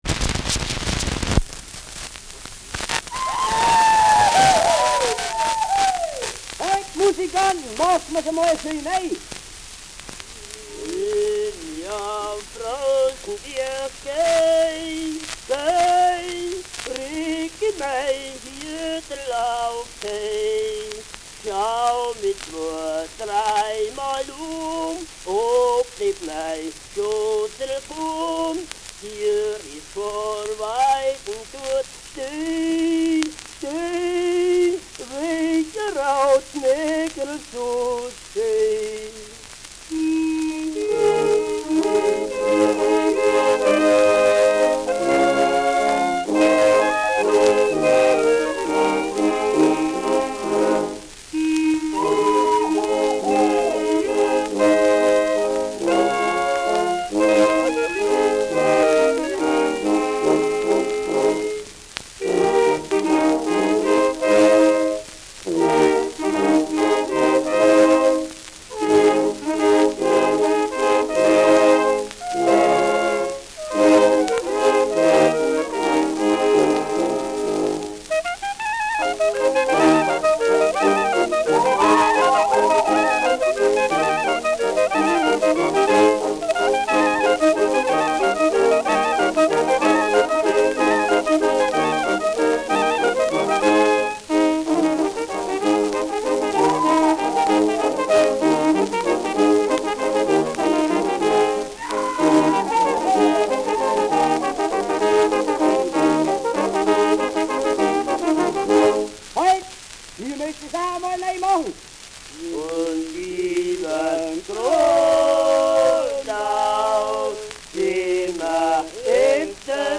Schellackplatte
Leichtes Knacken gegen Ende
Imitiert das charakteristische „Einmachen“.